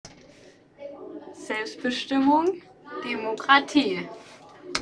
Standort der Erzählbox:
MS Wissenschaft @ Diverse Häfen
Der Anlass war MS Wissenschaft